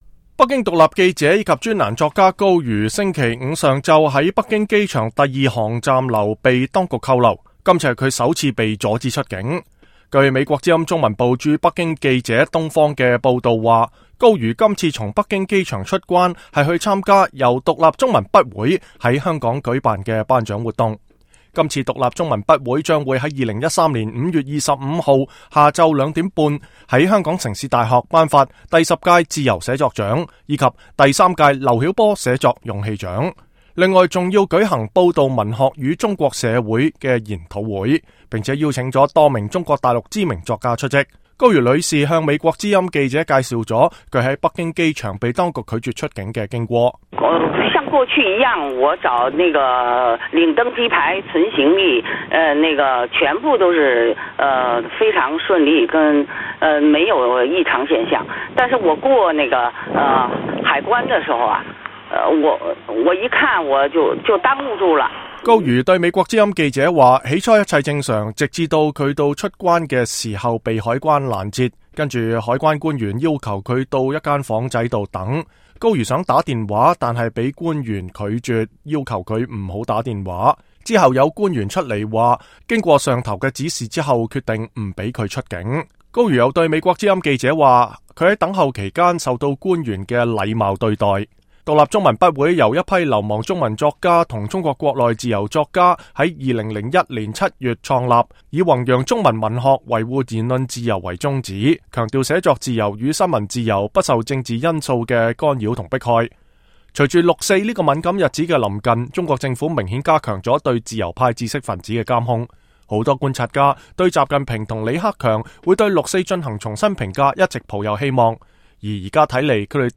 高瑜在電話當中向美國之音記者介紹了她在北京機場被拒絕出境的經過。